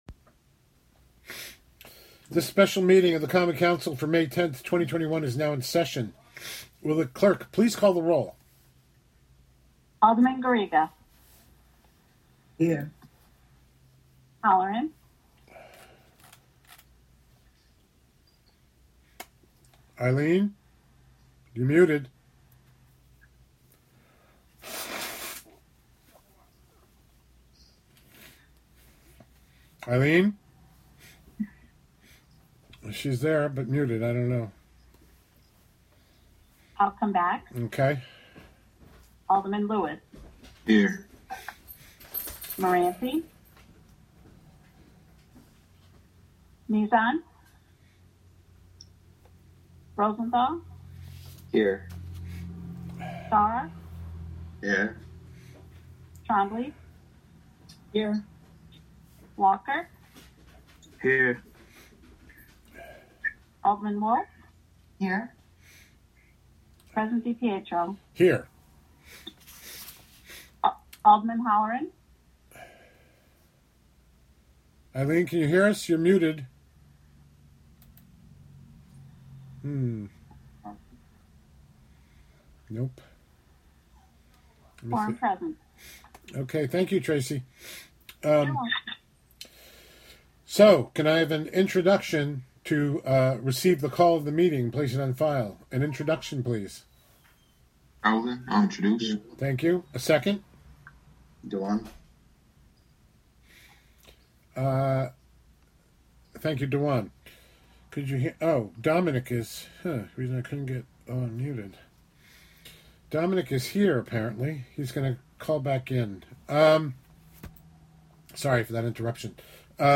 Live from the City of Hudson: Hudson Common Council (Audio)